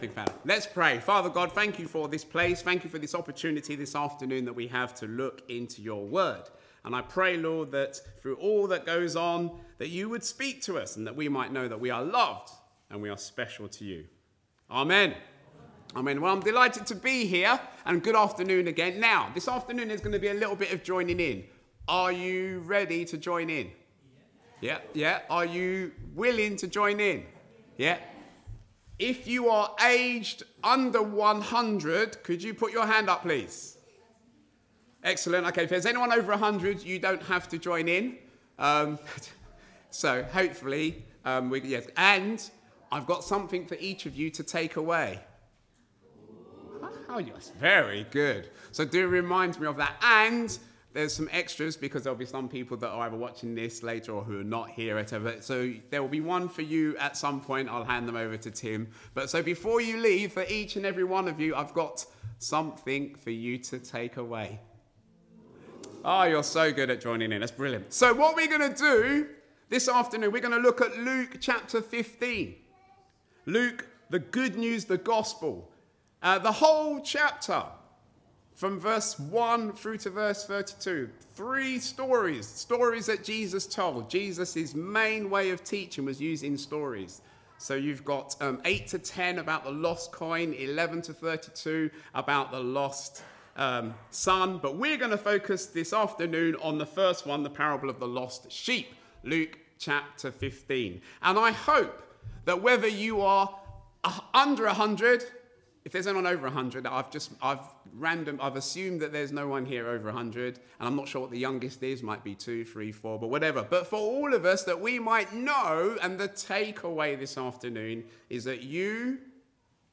Passage: Luke 15:1-7 Service Type: Weekly Service at 4pm